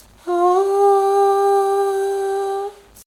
here’s the processed audio, maybe there would be more interesting samples to use, but I tried a few and they all basically sounded like this. Note the little noise bursts at the beginning and end, which is the 8-bit dithering noise being expanded into full-spectrum noise.